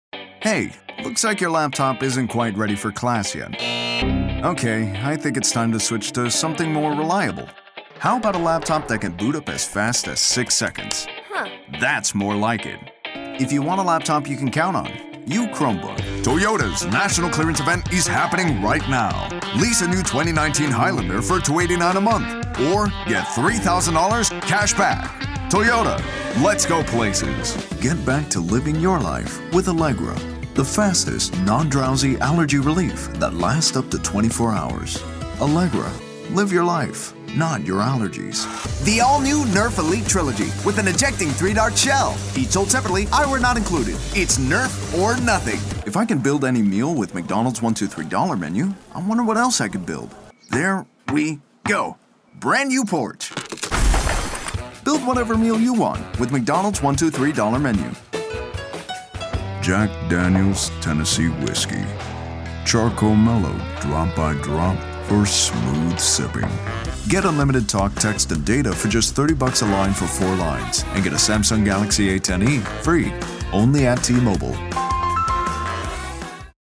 Teenager, Young Adult, Adult, Mature Adult
COMMERCIAL 💸
conversational
gravitas